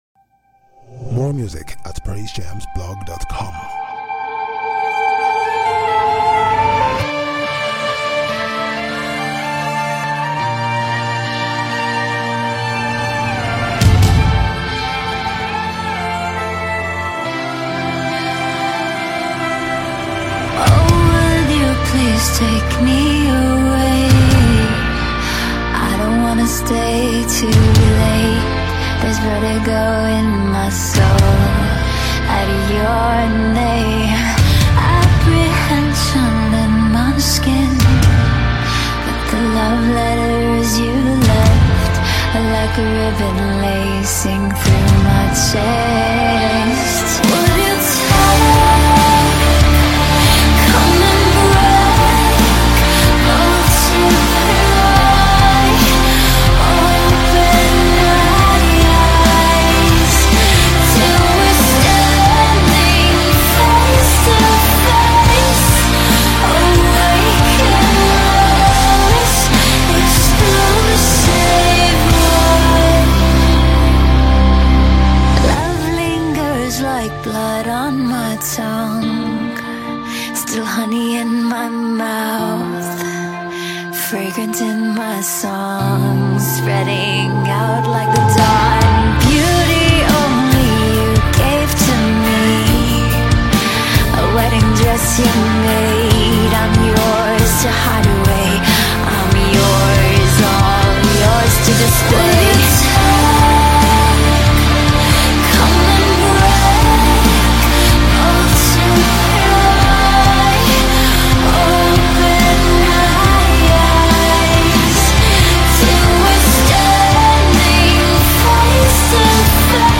Record-setting Christian hard rocker
Both atmospheric and intimate
is a string-driven ballad
The song developed into a sweeping chorus and vivid verses